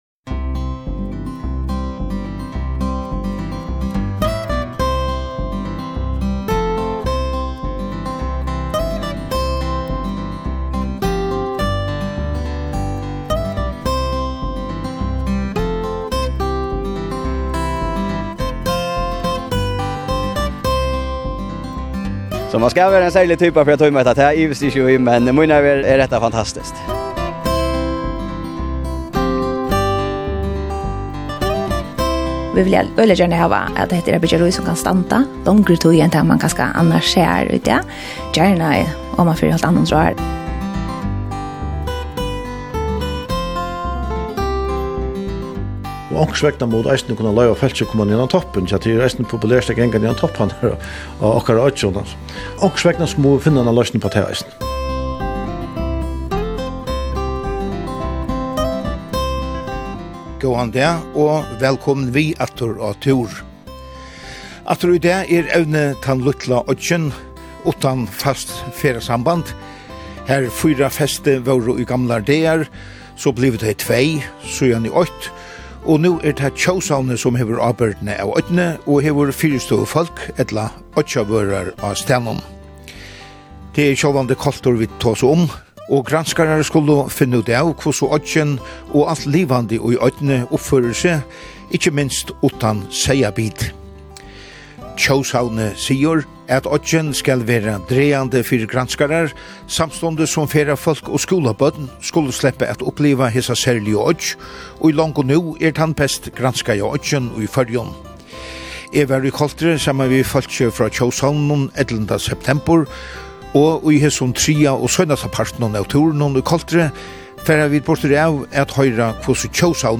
Tað eru nógvar smærri bygdir kring landið, sum sjáldan fáa pláss í miðlunum, men sum avgjørt hava sína søgu. Og hvørt menniskja hevur eina søgu at fortelja. Tað hoyra vit í reportasjunum í Útvarpinum mánadag klokkan 17:05.